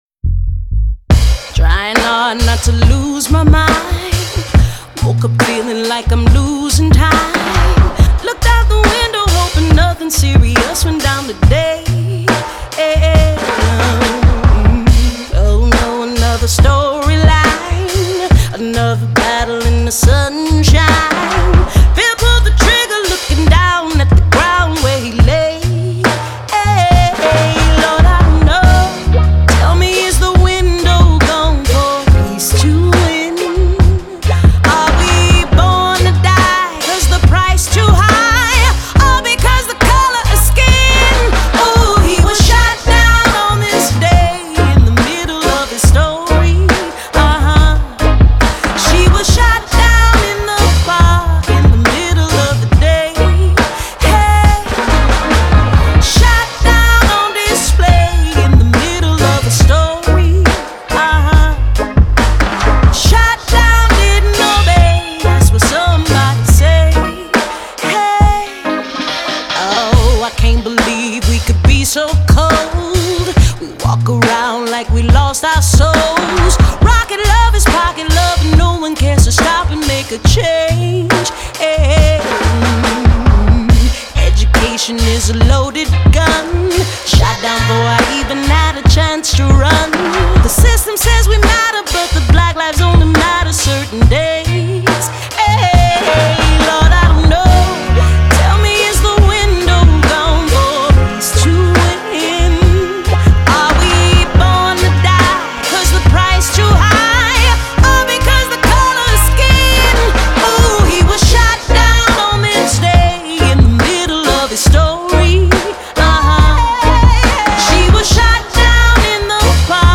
The vocals on this album are amazing.